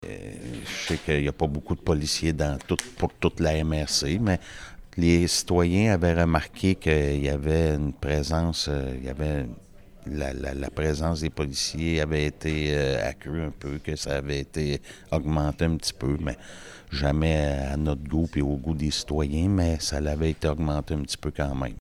La Ville a aussi demandé à la Sureté du Québec d’être plus présente dans ce secteur, ce qui semble avoir eu un certain effet comme l’a expliqué le conseiller Marion Lamothe.